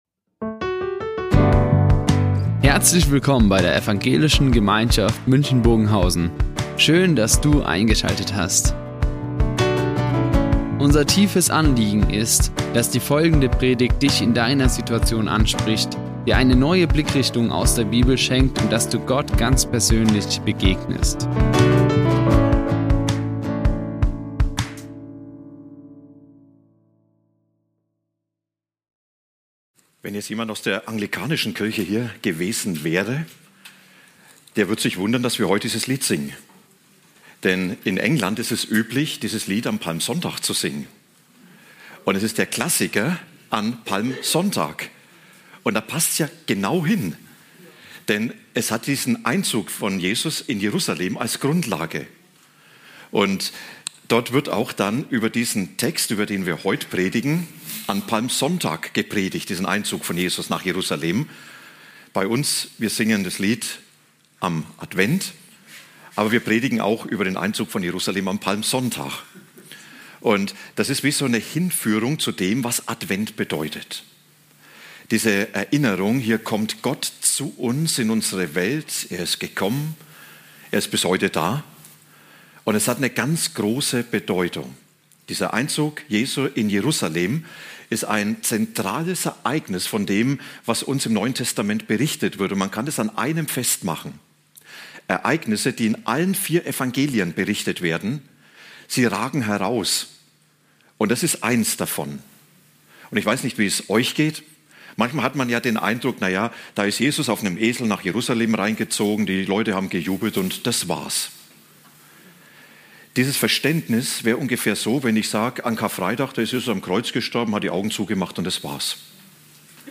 1-11 zum Thema "Gott kommt zu uns" Die Aufzeichnung erfolgte im Rahmen eines Livestreams.